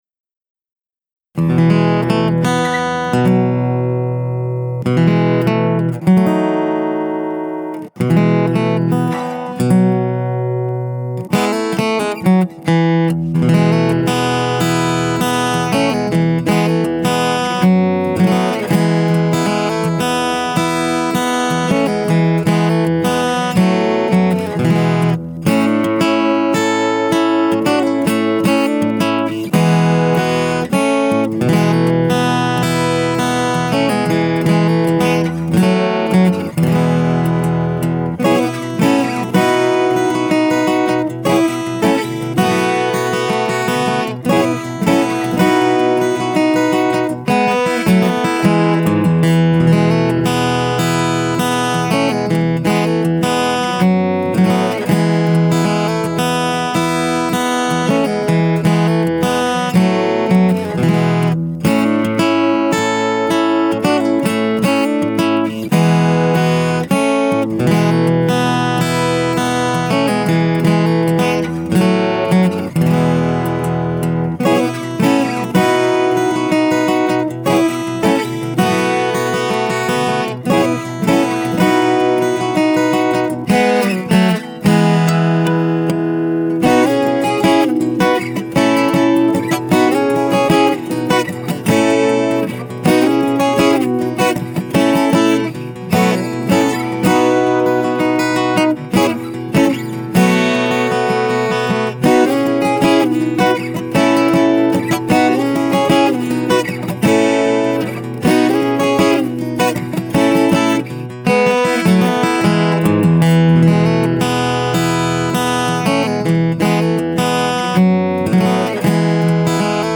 Instruments used were: my Gibson ES330 and Martin 000-15S for the rhythm parts, my Gibson Les Paul Traditional for the chorus and verse guitar riffs, my Roland Juno 61 for the piano parts, my Martin 000-15S for the acoustic melody, my Gibson SG for the guitar solo, and my Squier Precision Bass for, you guessed it, the bass track.